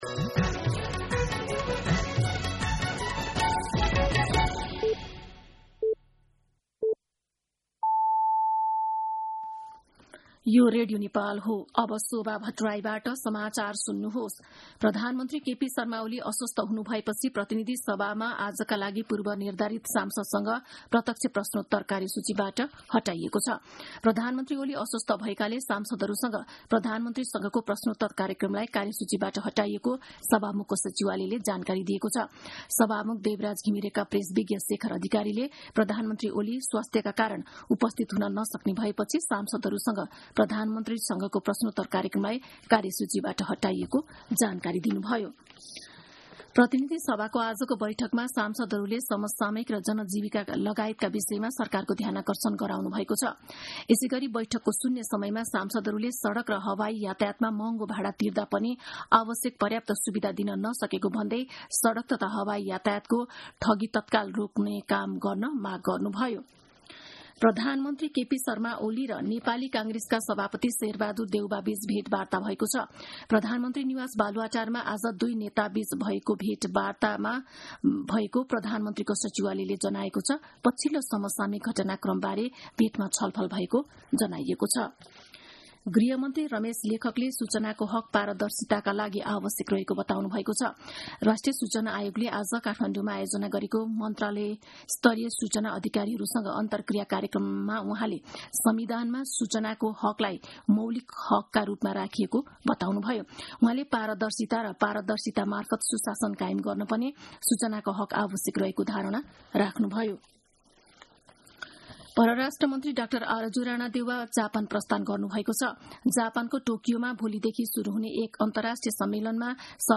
दिउँसो ४ बजेको नेपाली समाचार : ६ जेठ , २०८२